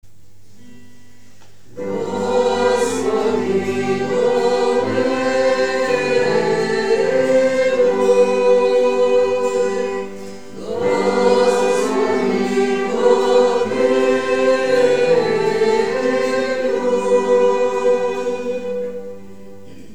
Pregària de Taizé
Capella de les Concepcionistes de Sant Josep - Diumenge 24 de novembre de 2013